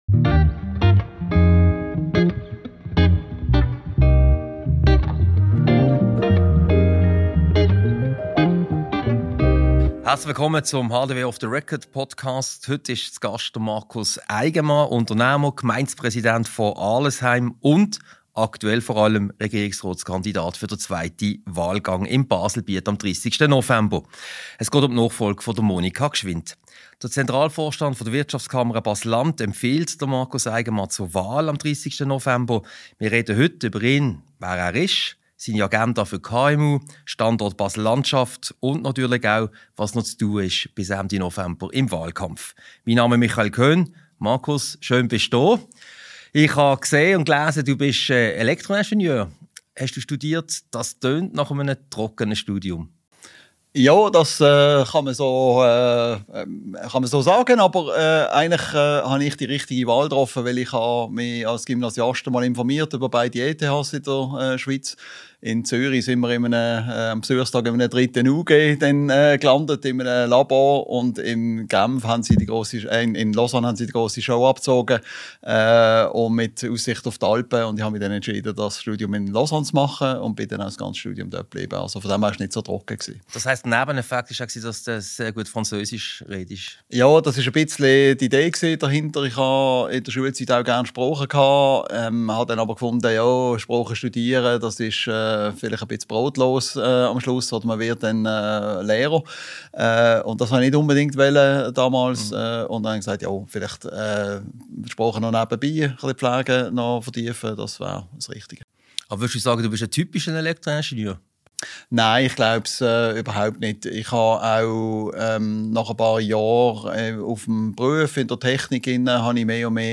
Diese Podcast-Ausgabe wurde als Video-Podcast im Multimedia-Studio im Haus der Wirtschaft HDW aufgezeichnet.